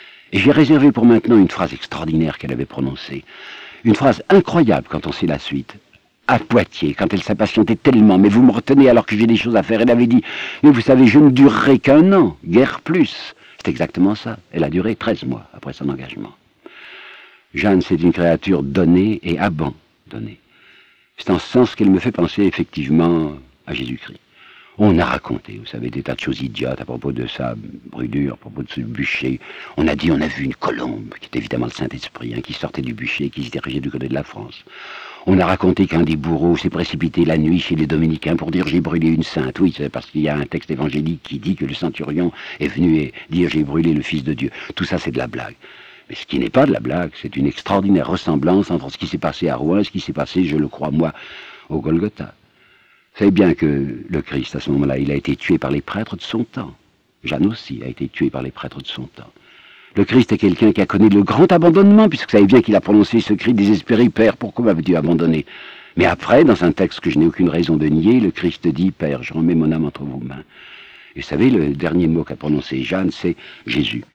Conférence audio.